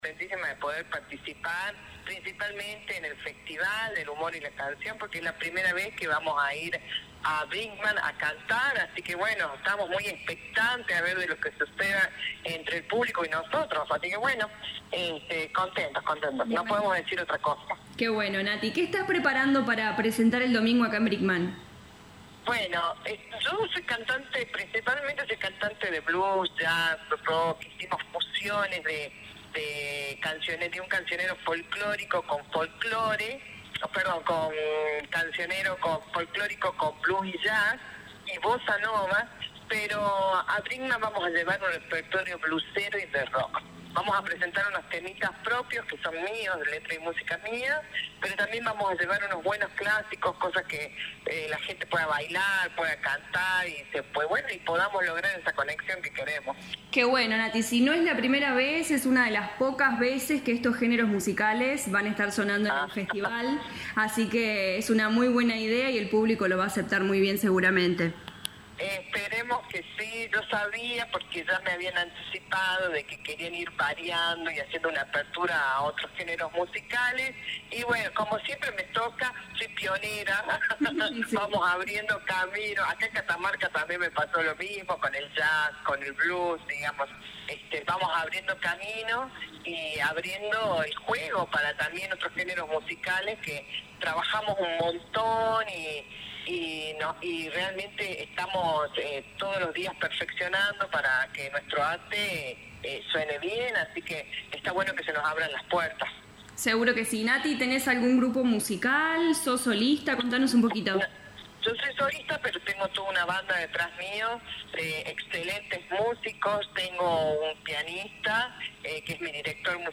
La artista dialogó con LA RADIO 102.9 FM y contó que su género es rock and roll y blues e innovará en el escenario Luis Landriscina con un variado repertorio musical.